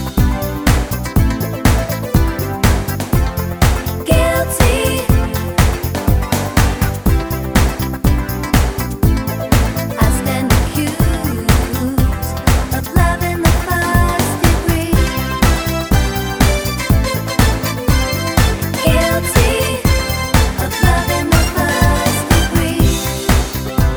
No Guitars Pop (1980s) 3:30 Buy £1.50